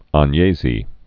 (än-yāzē)